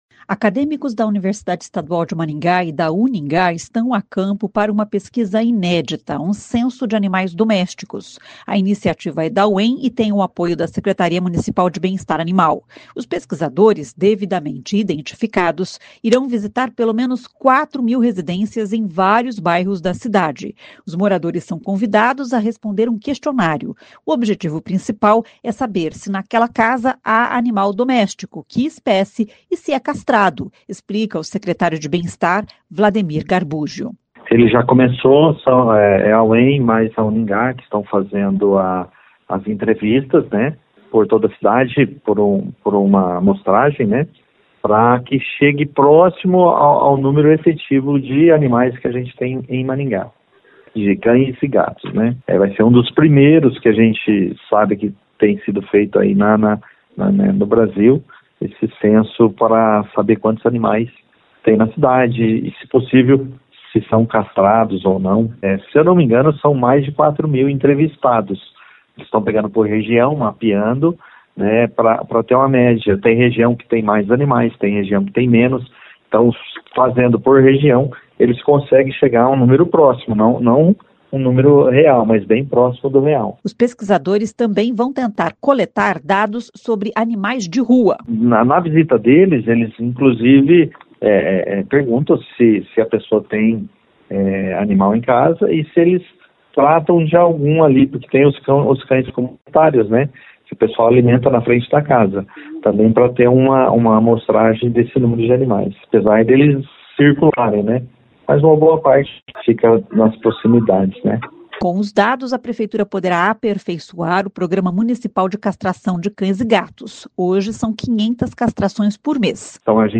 O objetivo principal é saber se naquela casa há animal doméstico, que espécie, e se é castrado, explica o secretário de Bem-Estar Wlademir Garbúggio.